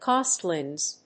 音節cost･li･ness発音記号・読み方kɔ́(ː)stlinəs